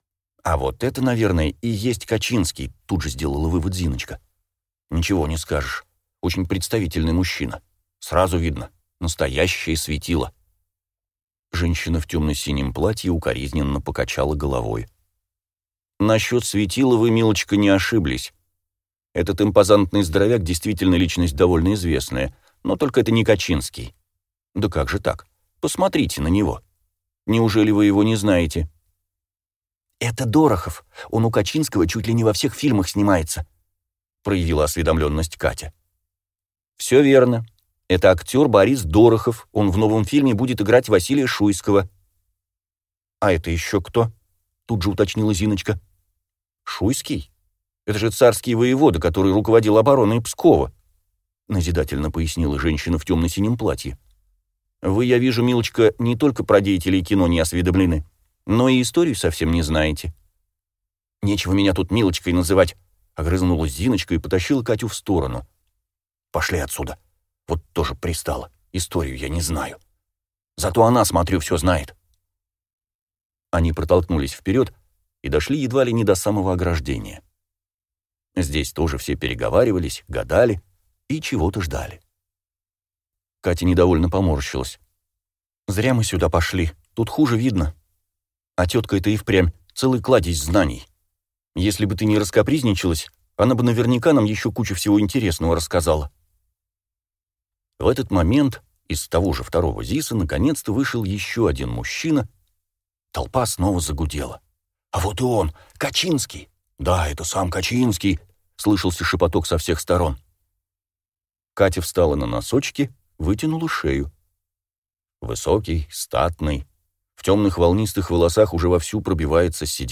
Аудиокнига Ядовитое кино | Библиотека аудиокниг